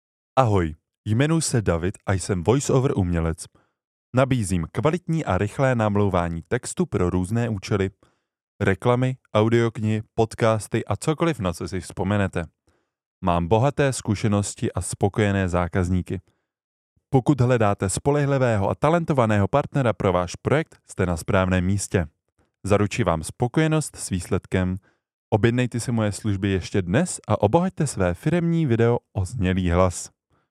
Voiceover pro váš projekt
Uvodni slovo.mp3